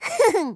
Laugh.wav